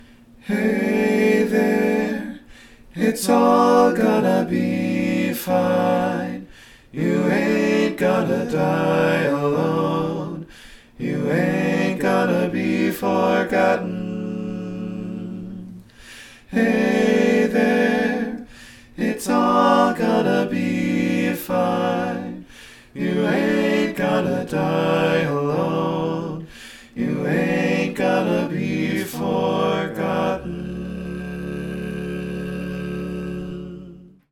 Key written in: D Major
How many parts: 4
Type: Barbershop
All Parts mix: